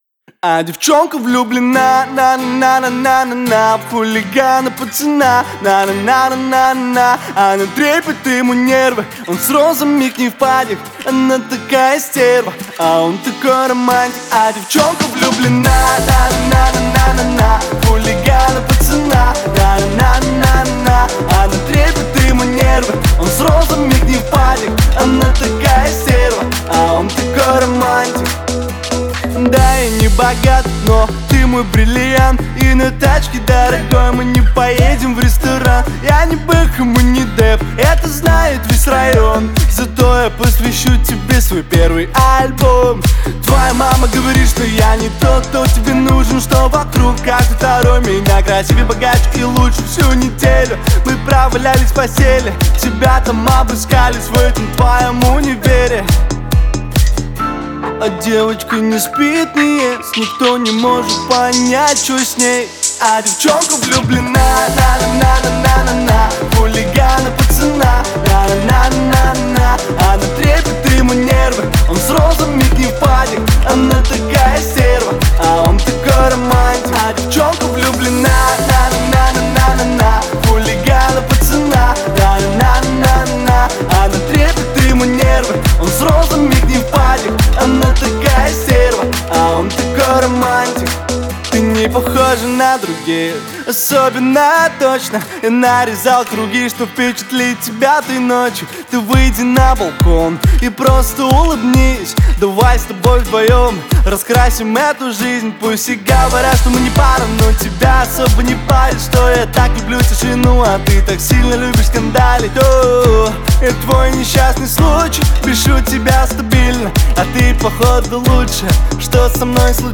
это зажигательная композиция в жанре поп с элементами рока